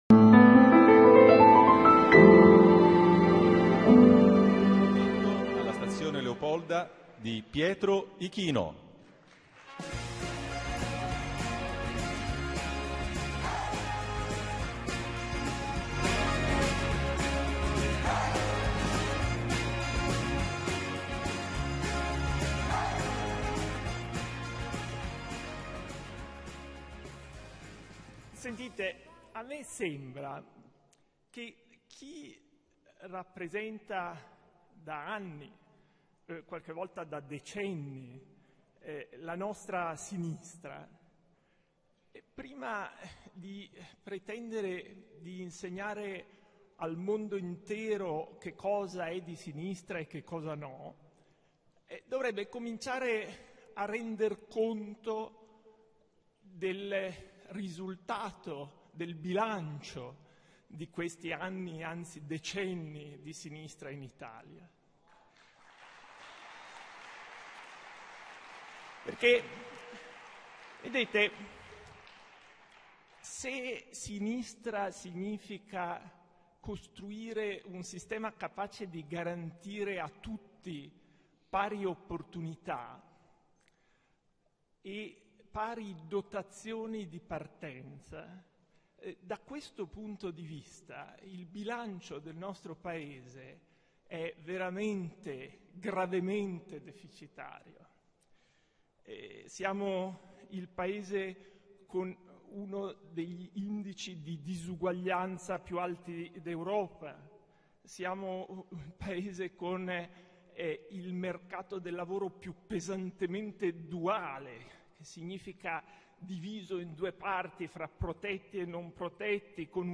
leopolda-2012-pietro-ichino.mp3